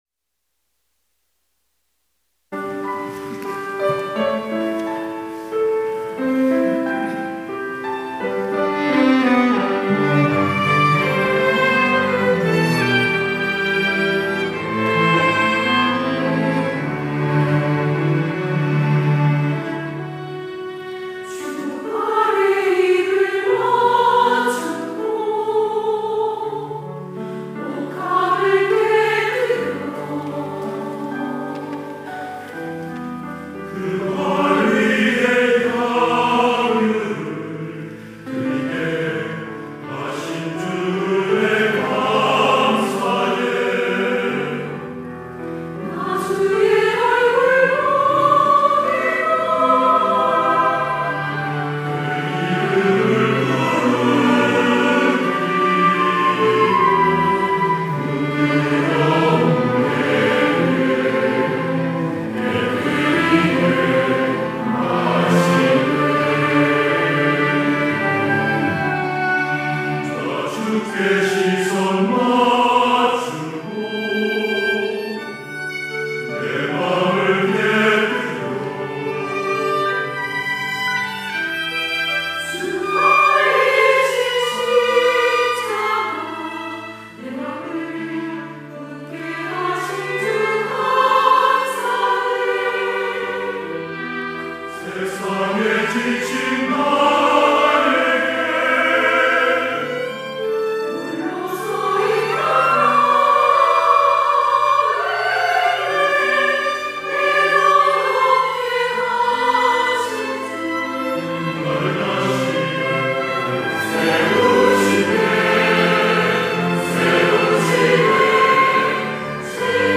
할렐루야(주일2부) - 옥합
찬양대